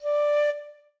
flute_d.ogg